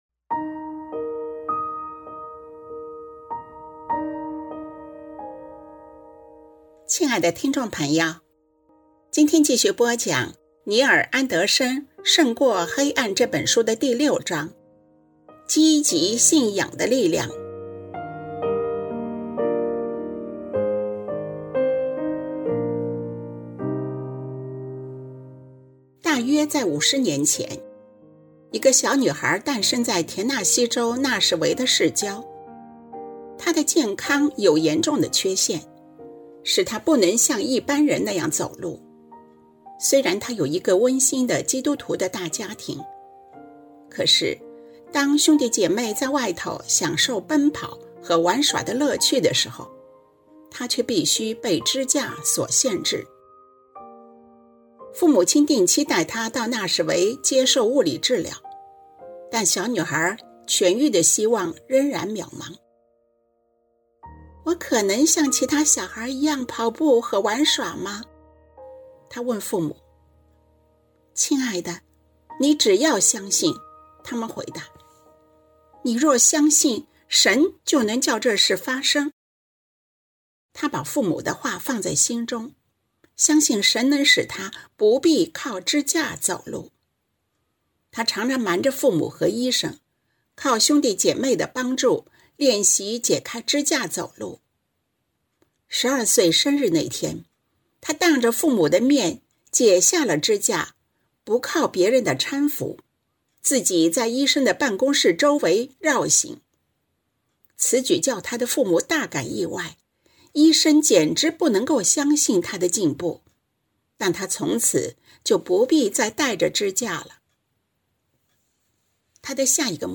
作者：尼尔·安德生 亲爱的听众朋友，今天继续播讲尼尔·安德生《胜过黑暗》的第六章：积极信仰的力量